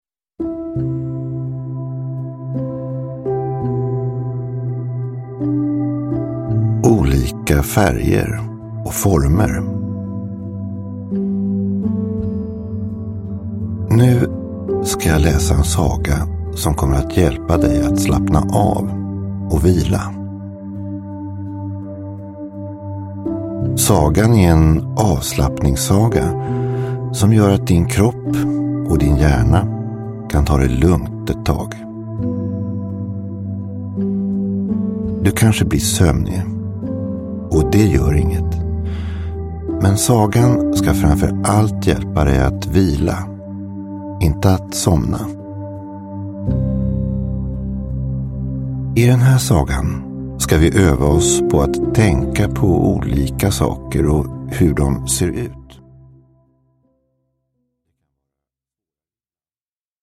Avslappningssagor. Färger och former – Ljudbok – Laddas ner
Uppläsare: